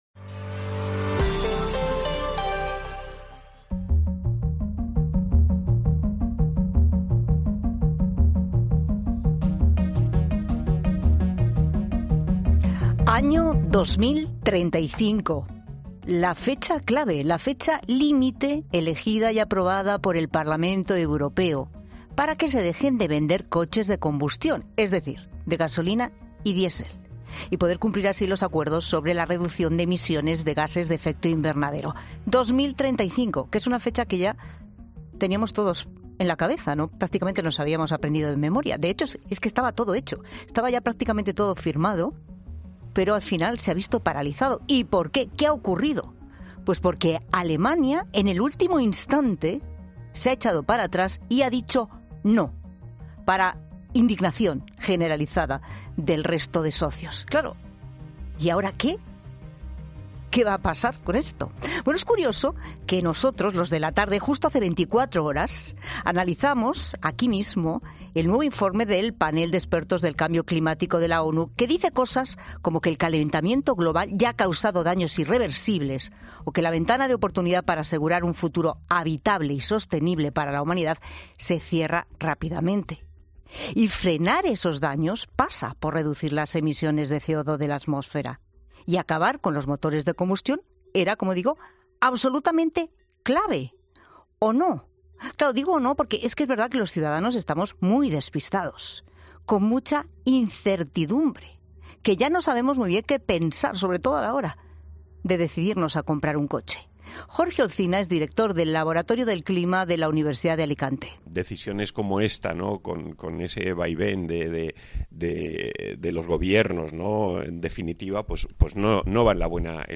Un físico e investigador del CSIC explica en La Tarde las ventajas e inconvenientes de usar el e-fuel o combustible sintético como alternativa.